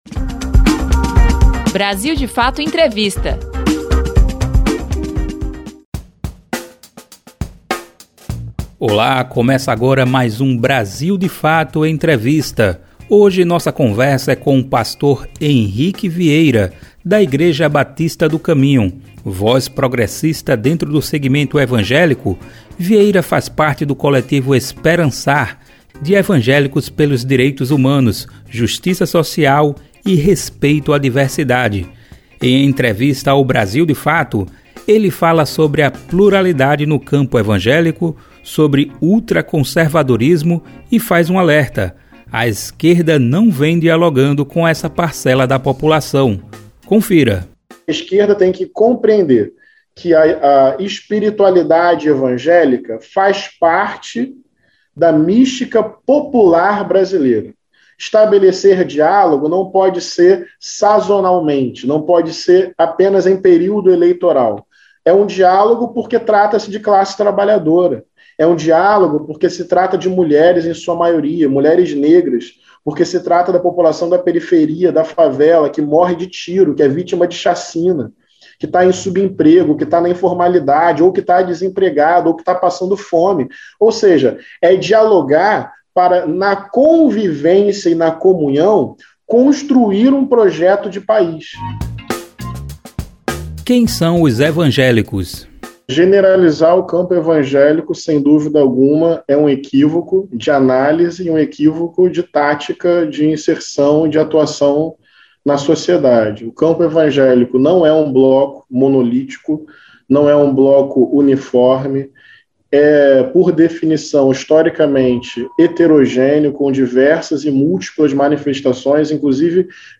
O BdF Entrevista desta semana conversa com Henrique Vieira, 34 anos, pastor da Igreja Batista do Caminho. Integrante do Movimento Negro Evangélico (MNE), Vieira fala sobre conservadorismo no segmento evangélico, Teologia Negra, e desafios para a esquerda no diálogo político com essa parcela da sociedade que representa 30% da população brasileira.